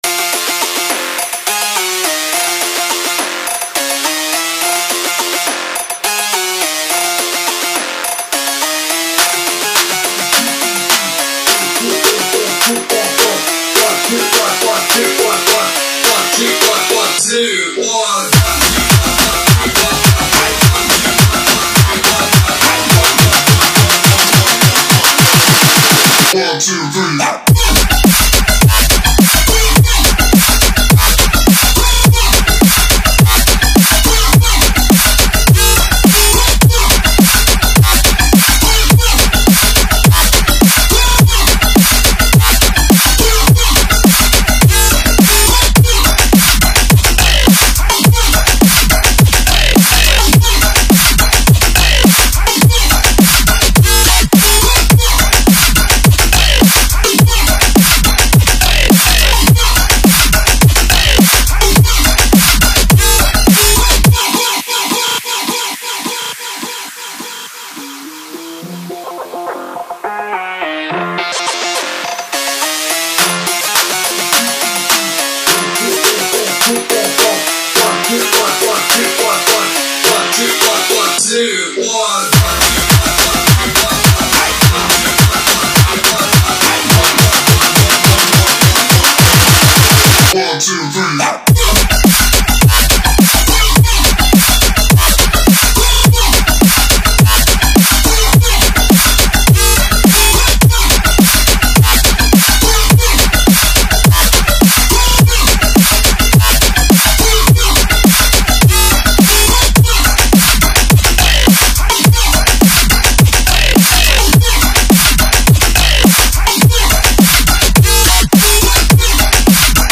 Dubstep, Angry, Dark, Quirky, Weird, Energetic